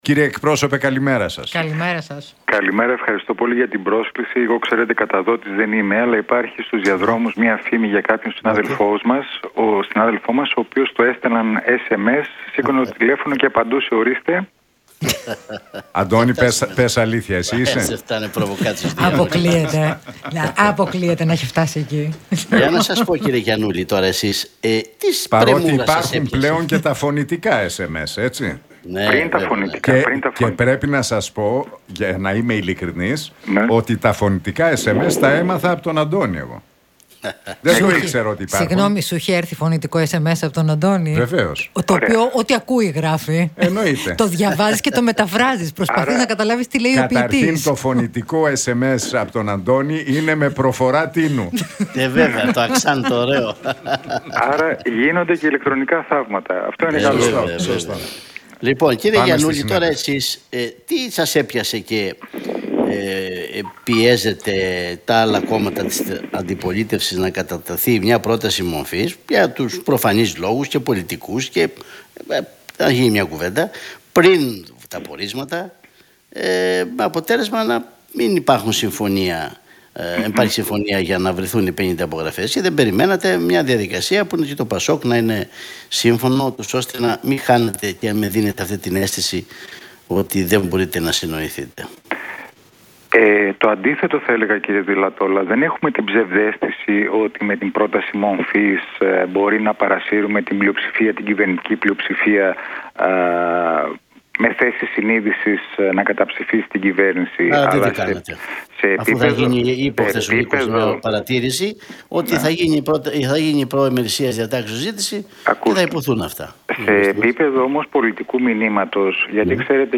Γιαννούλης στον Realfm 97,8: Δεν έχουμε την ψευδαίσθηση ότι με την πρόταση μομφής μπορεί να παρασύρουμε την ΝΔ να καταψηφίσει την κυβέρνηση